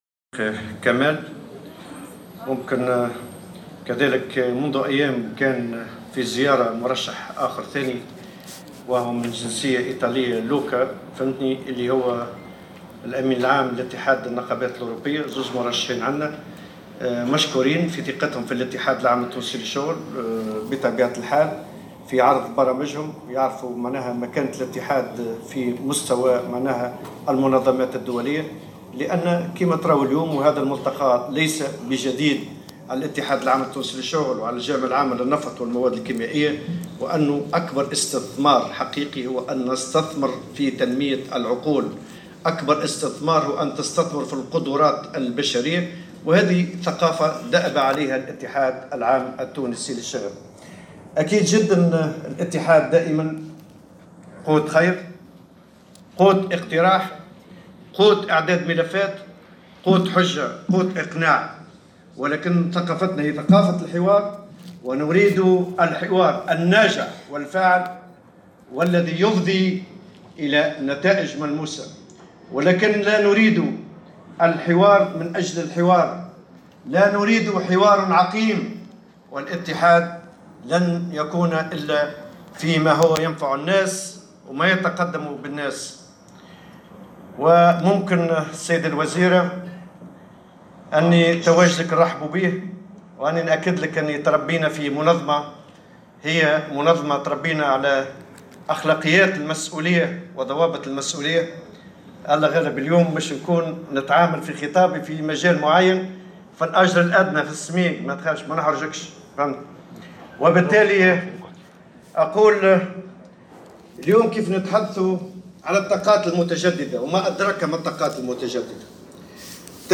قال الأمين العام للاتحاد العام التونسي للشغل، نور الدين الطبوبي، خلال كلمته على هامش ندوة قطاعية تحت عنوان " تقنيات التفاوض ومنظومة الدعم في المحروقات " بالحمامات، اليوم السبت، إن الاتحاد لا يرغب في الحوار فقط من أجل الحوار، ويلعب دائما دوره كقوة اقتراح في حوارات ناجعة تفضي إلى نتائج ملموسة.